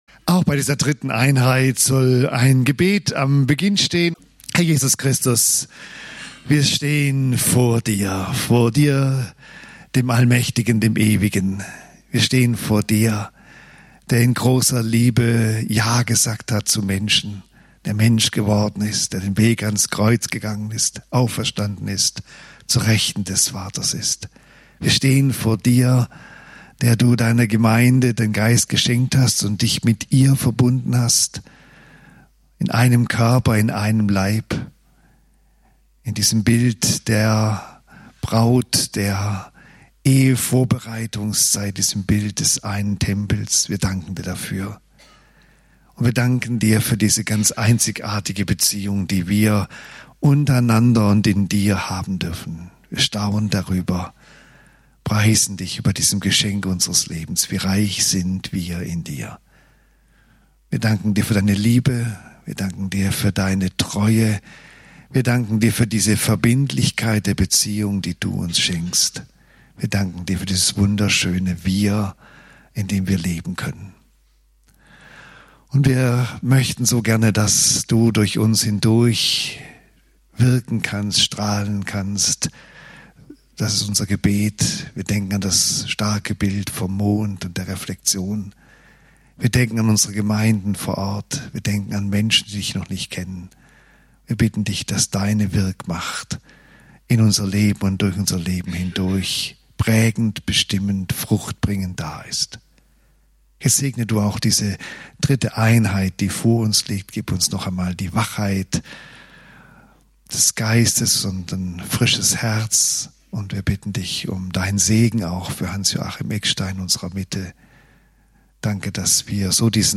Tagesseminar: "Lasset uns wachsen!" - Das Geheimnis der Gemeinde nach dem Neuen Testament (Eph. 4, 15+16)